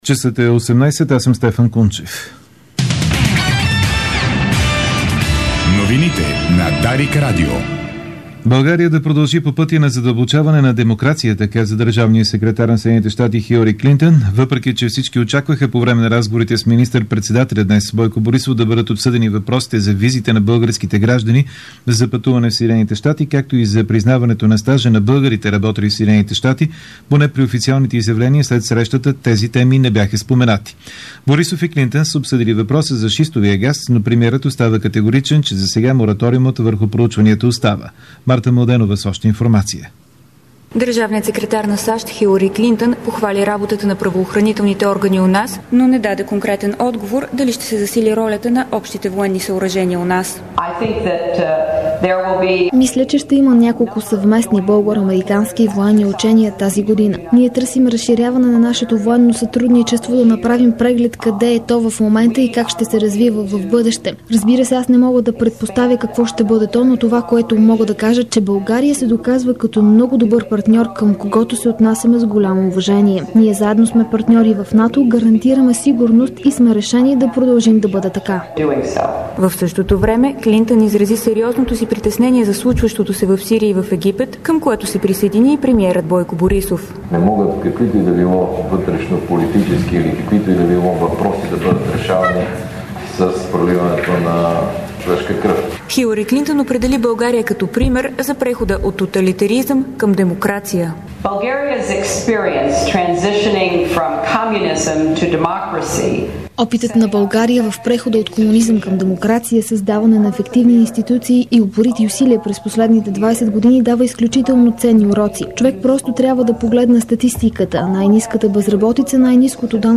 Обзорна информационна емисия - 05.02.2012